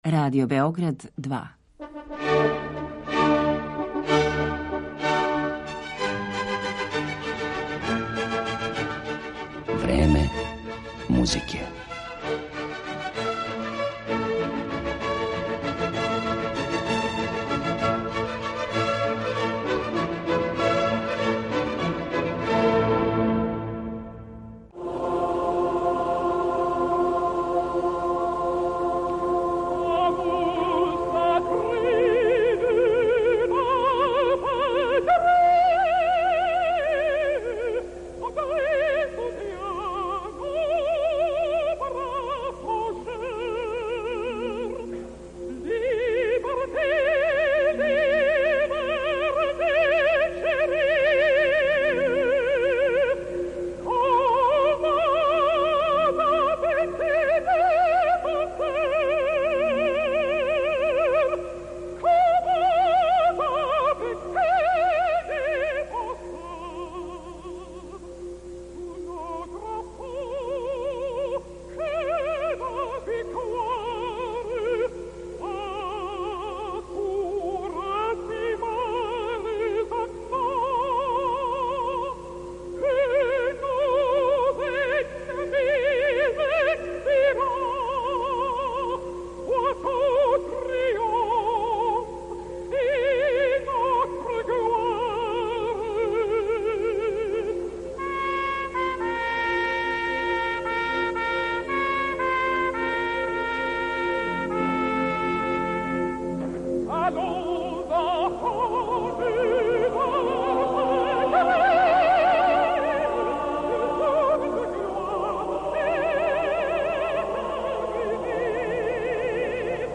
Њен аутор је Клод Жозеф Руже де Лил, а чућете и музику коју су на основу „Марсељезе" компоновали, између осталих и Клод Бењин Балбастр, Роберт Шуман, Петар Иљич Чајковски, Ђоакино Росини, Клод Дебиси, Ерик Сати и Игор Стравински.